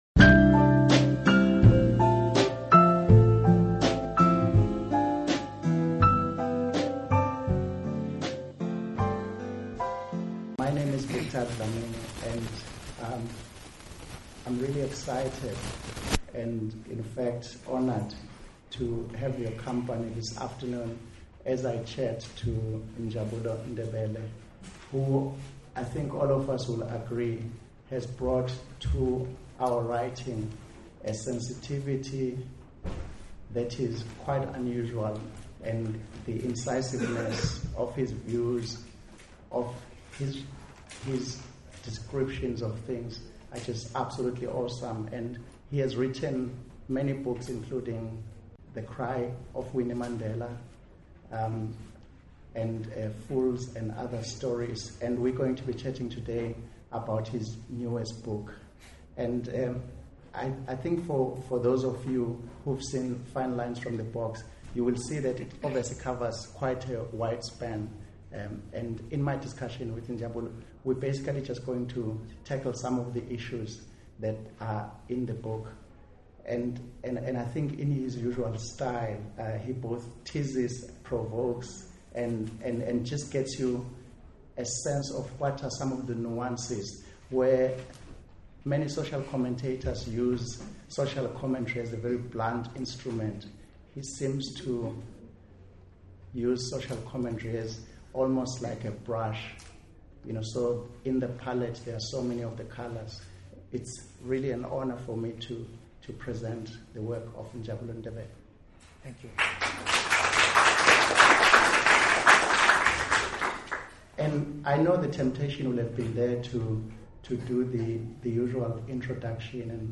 Interview
at the Cape Town Book Fair
DESCRIPTION: It was my great pleasure to interview Njabulo Simakahle Ndebele about his latest book, Fine Lines from the Box (Umuzi) at the recent Cape Town Book Fair. I’m thrilled that I now have the opportunity of sharing this wonderful conversation with you as a ‘live’ podcast, the second live podcast in my series of Literary Podcasts.